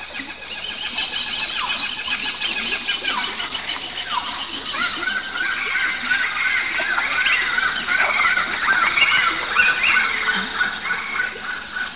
jungle.wav